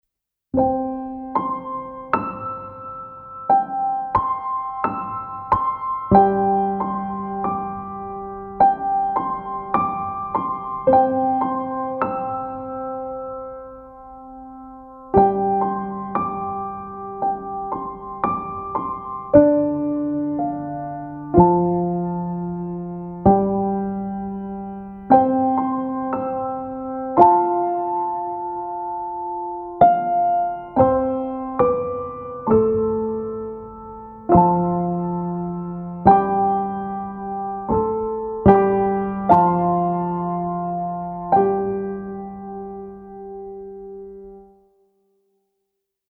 Instrumental version of song 5
intended to be used as a backing track for your performance.